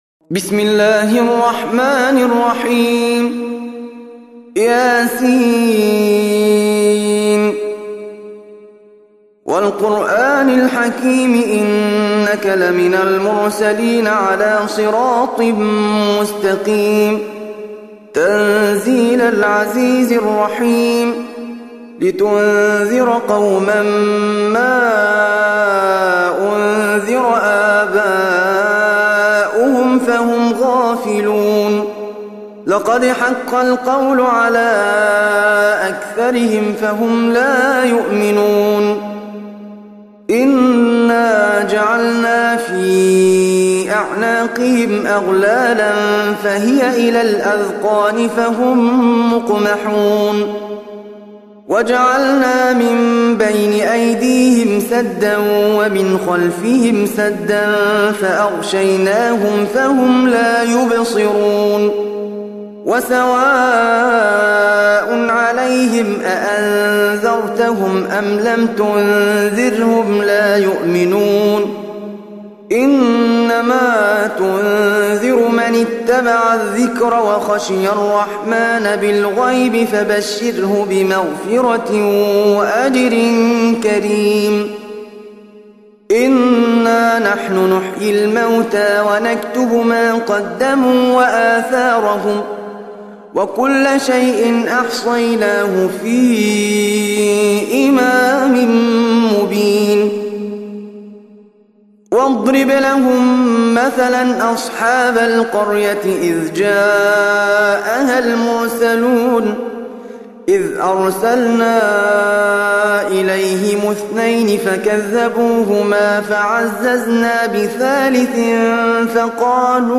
Surah Repeating تكرار السورة Download Surah حمّل السورة Reciting Murattalah Audio for 36. Surah Y�S�n. سورة يس N.B *Surah Includes Al-Basmalah Reciters Sequents تتابع التلاوات Reciters Repeats تكرار التلاوات